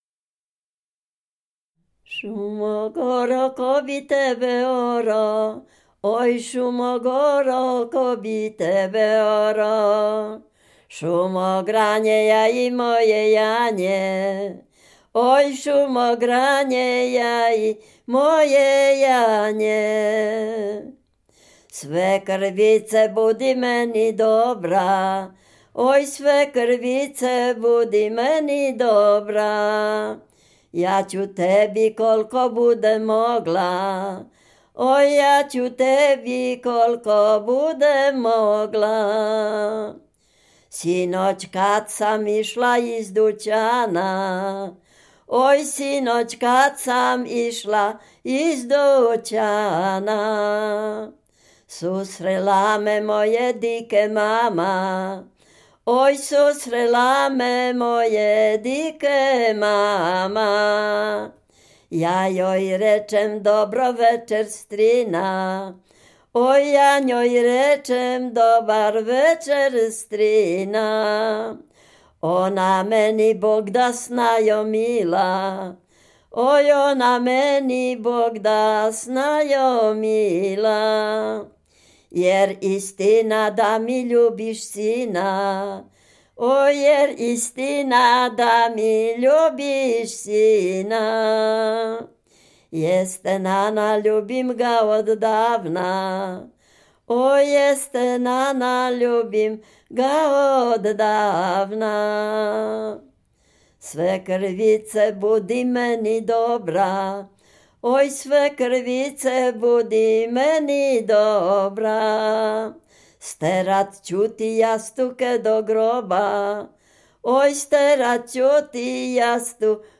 Dolny Śląsk, powiat bolesławiecki, gmina Nowogrodziec, wieś Zebrzydowa
liryczne miłosne